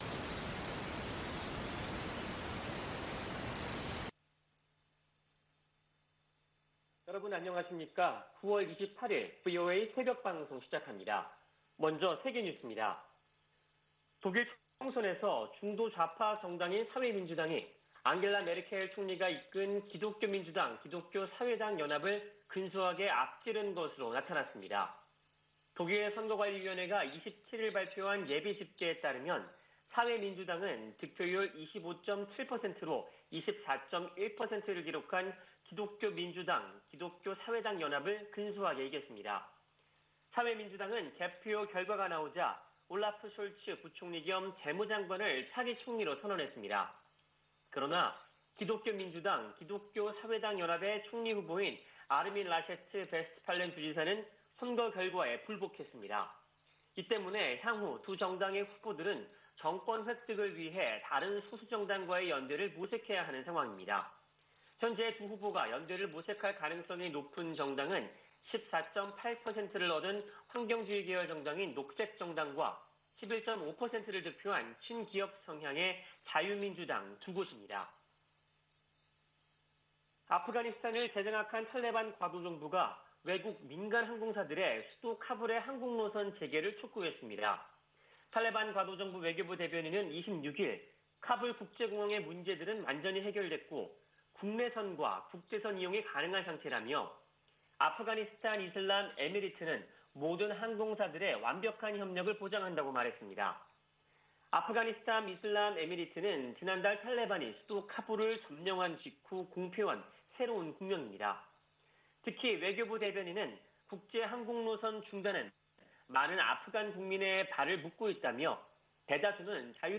VOA 한국어 '출발 뉴스 쇼', 2021년 9월 28일 방송입니다. 김여정 북한 노동당 중앙위원회 제1부부장이 연이틀 담화를 통해 유화적 메시지를 보내 주목됩니다. 북한은 제재 완화든 경제 발전이든 원하는 것이 있다면 협상장으로 나와야 한다고 미 국무부 부차관보가 지적했습니다. 국제원자력기구(IAEA)가 북한에 비핵화 이행을 촉구하고 국제사회의 외교적 노력을 지지하는 결의안을 채택했습니다.